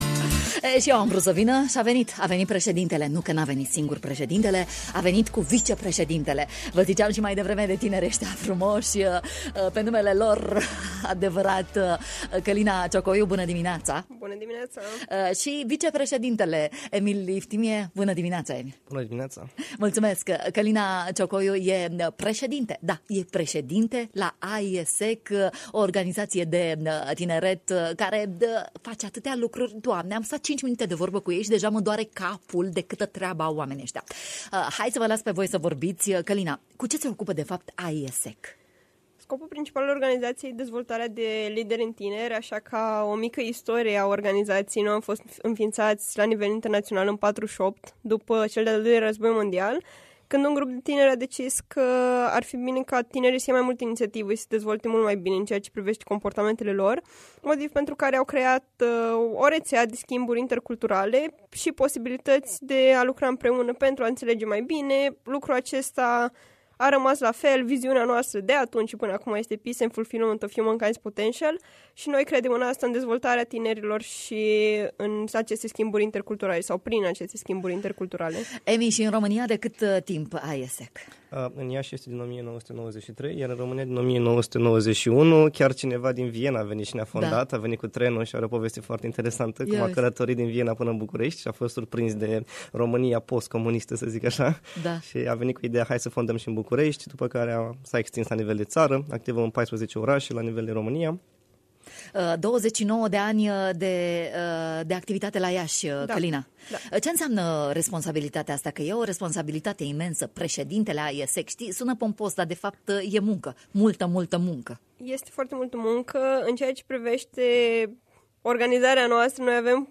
în matinalul de la Radio România Iași